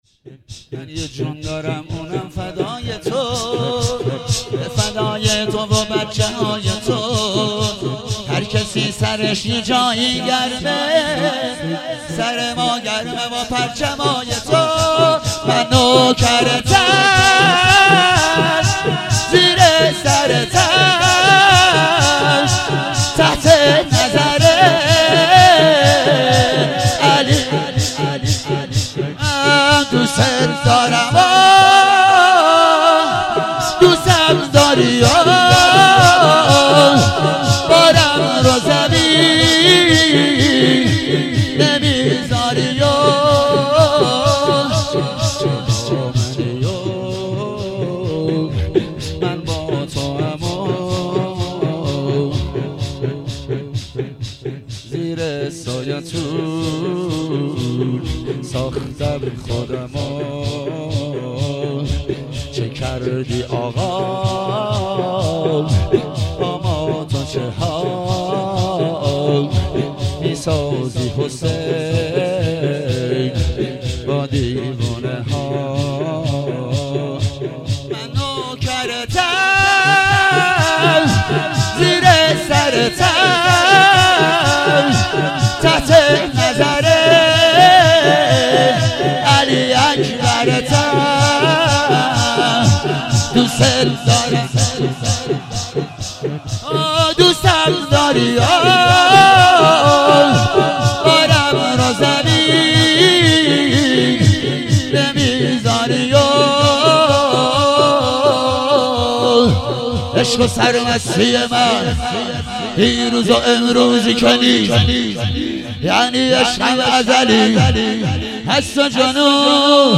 هفتگی زمستان 97